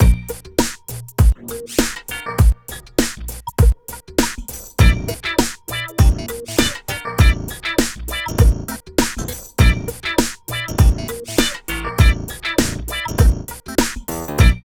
66 LOOP   -L.wav